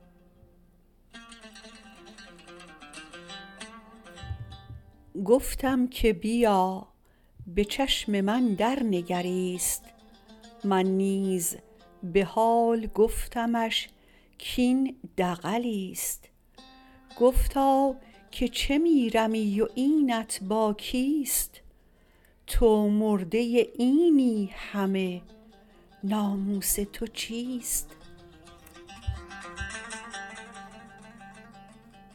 خوانش